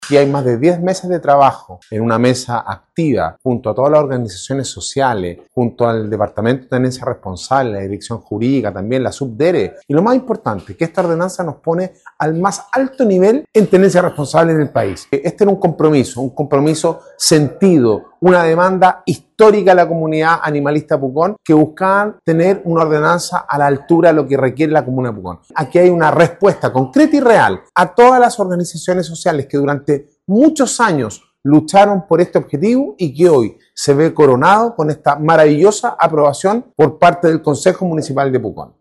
Alcalde-Sebastian-Alvarez-destaca-el-acuerdo-social-para-llegar-a-esta-normativa-.mp3